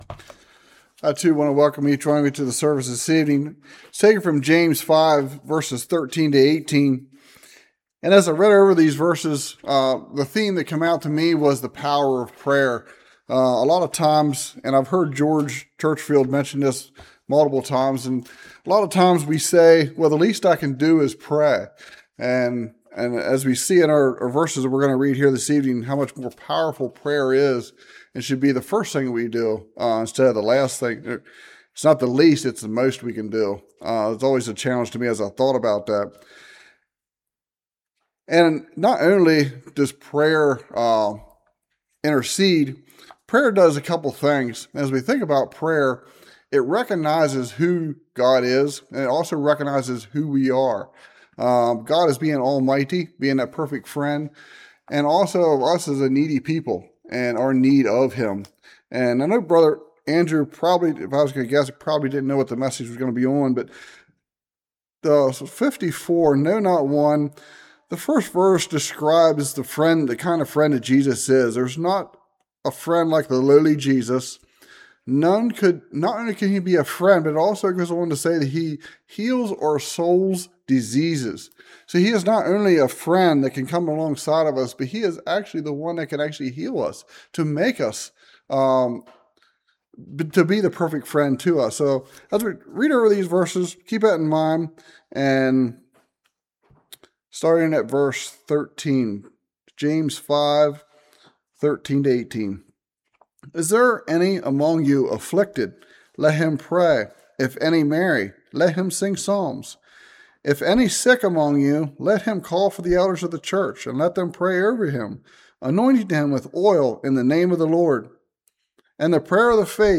James 5:13-18 Service Type: Evening There are two things that tell us if a prophet is true